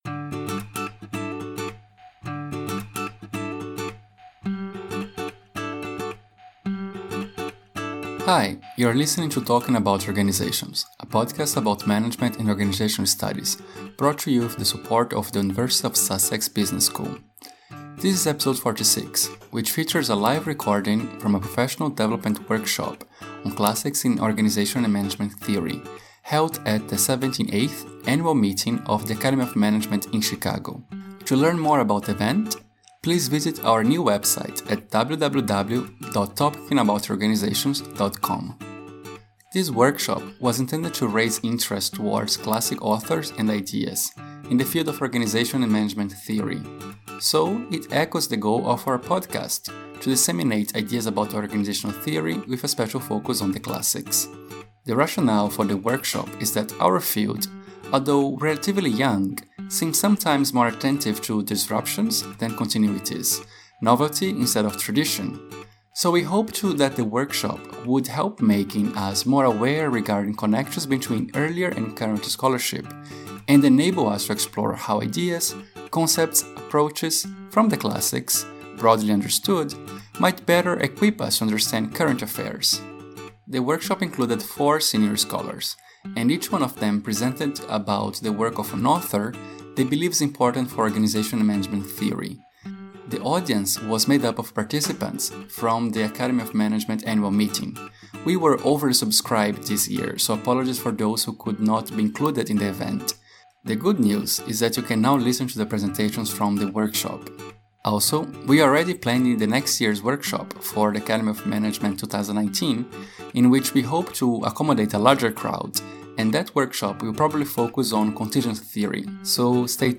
A special recording from a workshop on management classics held at the 2018 Academy of Management Conference in Chicago.
In the workshop, senior scholars presented talks on four classic authors (Karl Marx, Mary Parker Follett, Mary Douglas, and Albert Hirschman) to discuss their contemporary relevance. This was followed by a roundtable discussion limited to fifty participants.